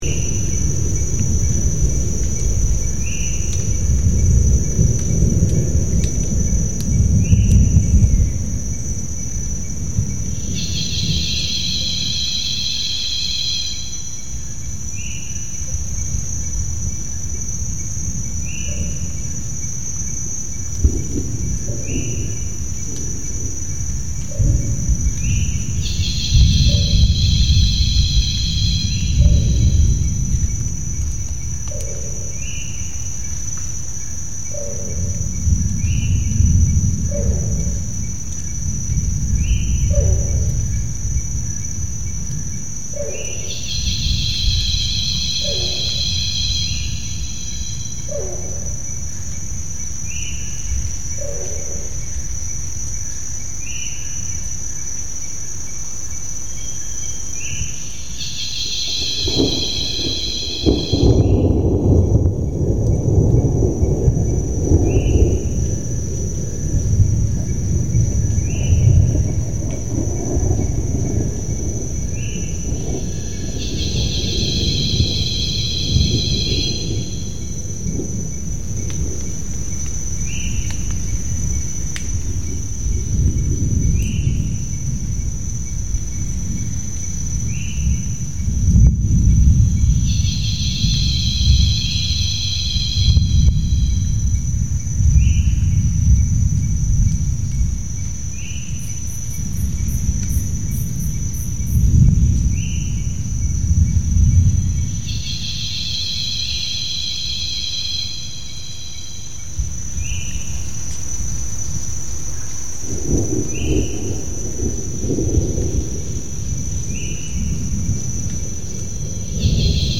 Touraco (bird) and distant thunder
Forest sounds with touraco (bird) and distant thunder.